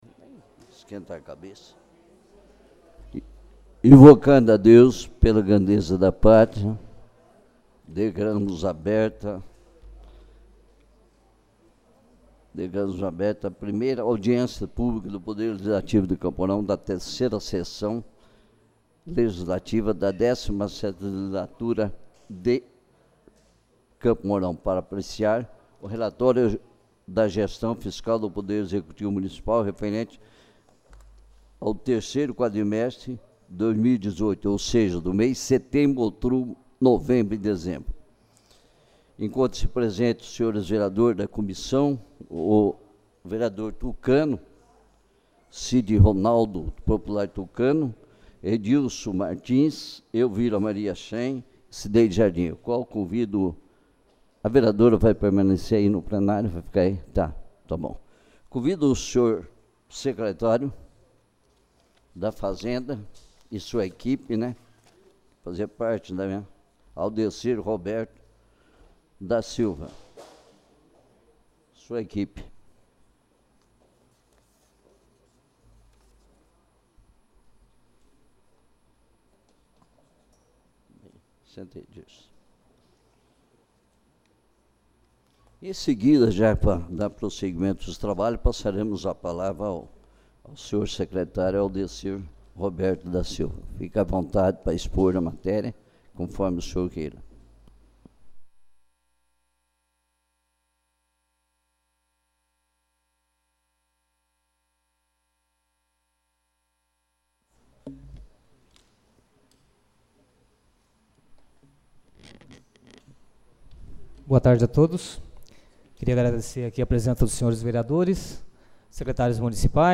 1ª Audiência Pública